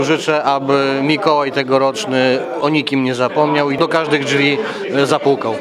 To już piękna świąteczna tradycja – wigilia w Radiu 5 Ełk.